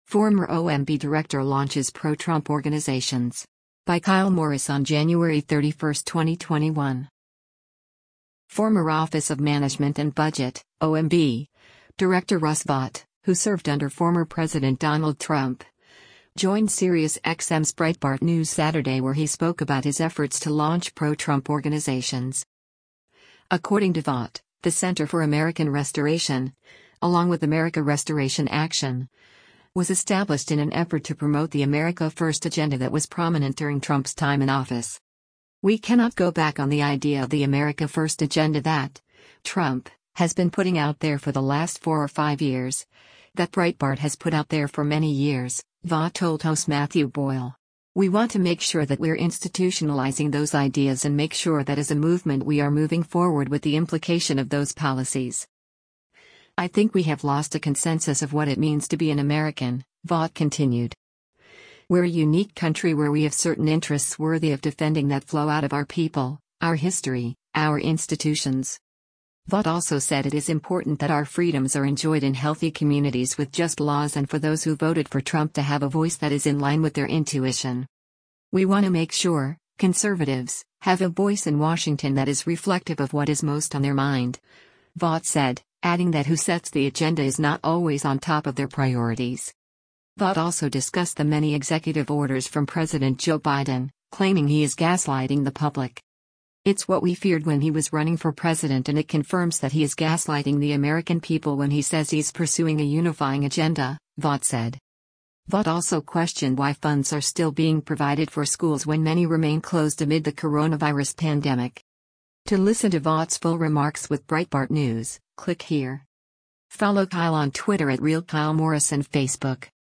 Former Office of Management and Budget (OMB) Director Russ Vought, who served under former President Donald Trump, joined SiriusXM’s Breitbart News Saturday where he spoke about his efforts to launch pro-Trump organizations.